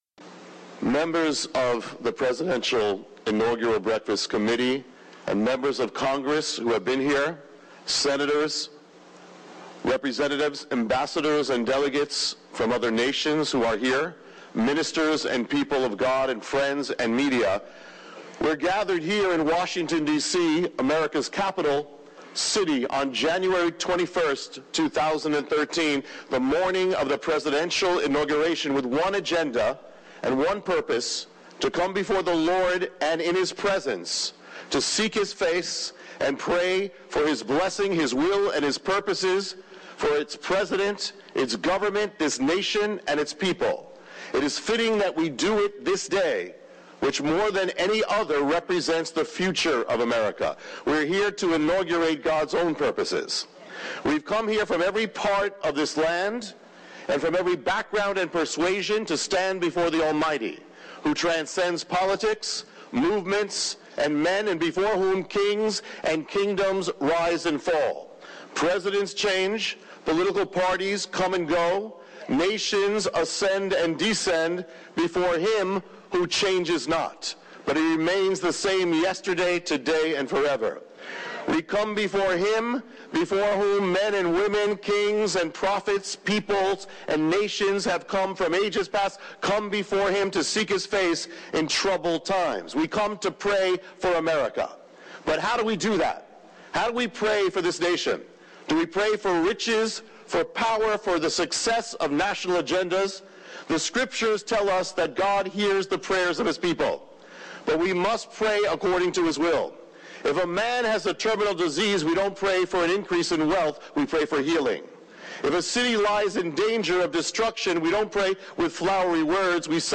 Addresses The Presidential Inaugural Prayer Breakfast